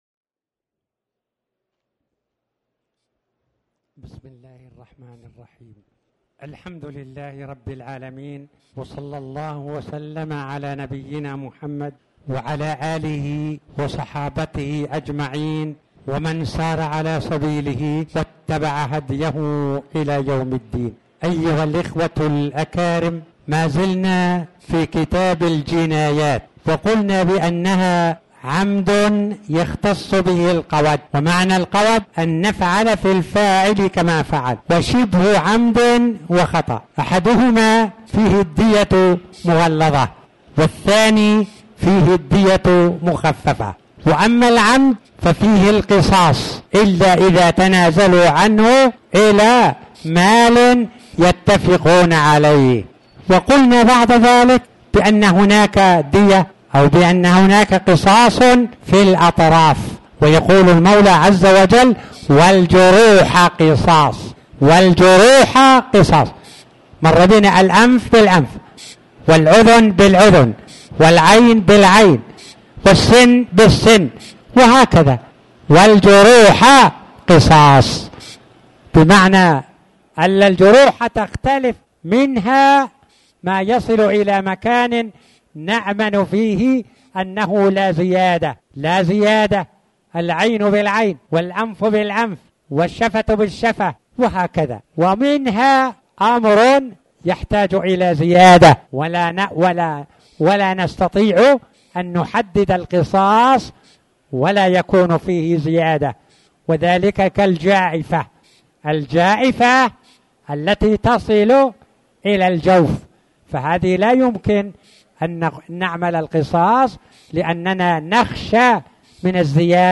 تاريخ النشر ٢٢ رجب ١٤٣٩ هـ المكان: المسجد الحرام الشيخ